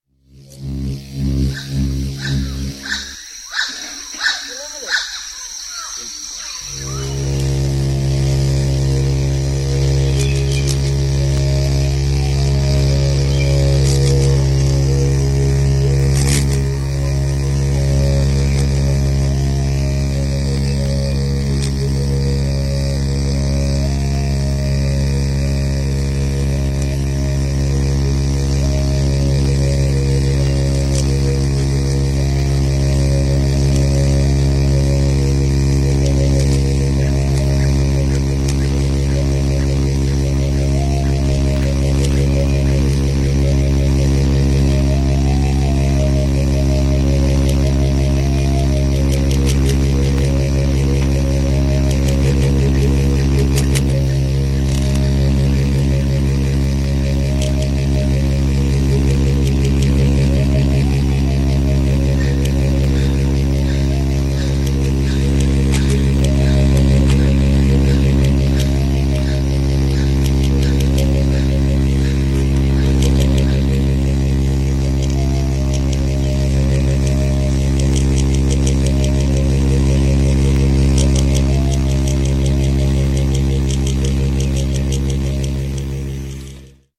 Binatang (sago beetle). Probably the first mouth-synthesizer, played by a Papua.
binatang.ogg